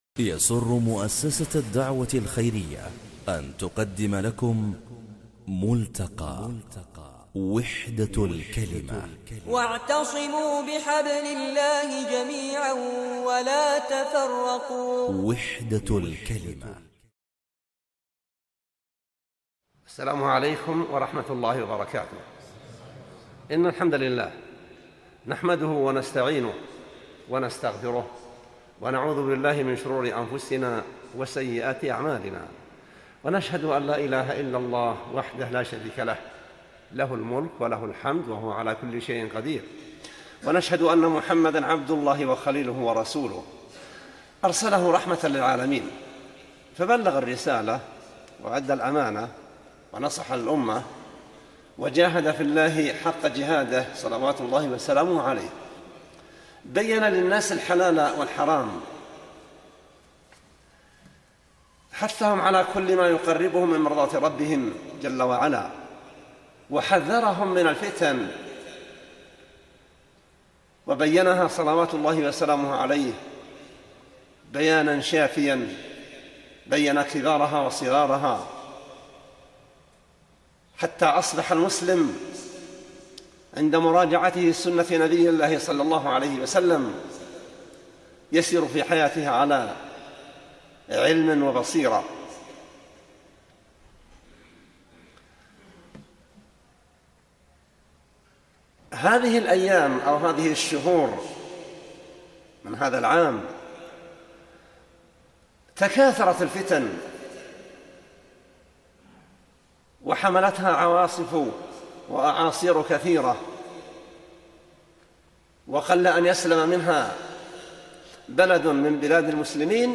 محاضرة - موقف المسلم من الفتن